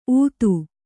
♪ ūtu